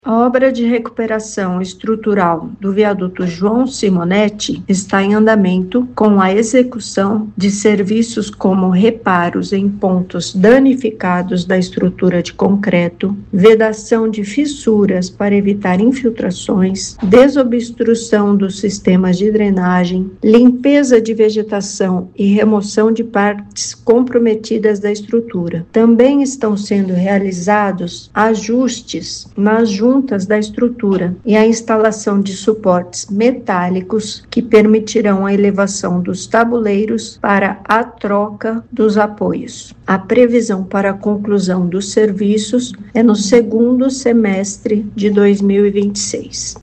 As obras de recuperação do viaduto João Simonetti, em Bauru, continuam sendo realizadas. Os serviços foram retomados em outubro do ano passado, com previsão de conclusão para julho deste ano, devido à complexidade dos trabalhos a serem executados. São reparos em diversos pontos da estrutura, como detalha a Secretária Municipal de Infraestrutura, Pérola Zanotto.